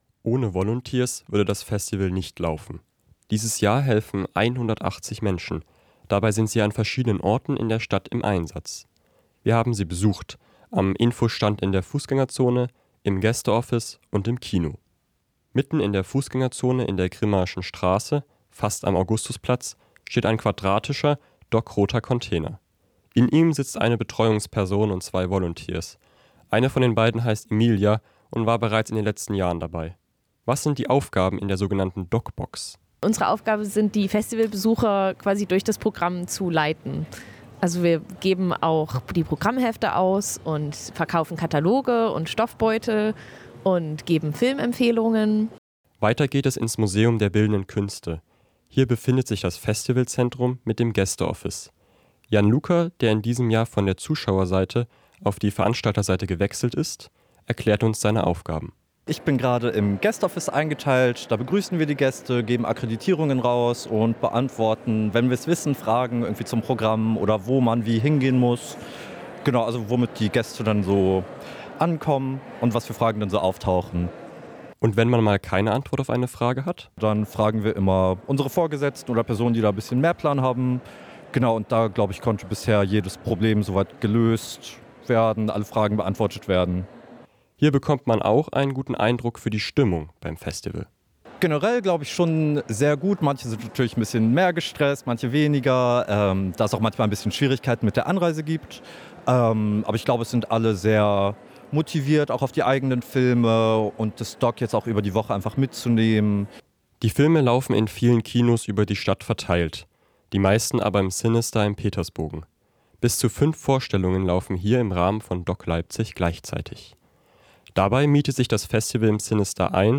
Die DOK Spotters haben sie begleitet – im Kino, im Gäste-Office und am Infostand in der Fußgängerzone. Wie sieht der Volunteer-Alltag eigentlich aus? Und wie nehmen die freiwilligen Helfer*innen die Festival-Stimmung wahr?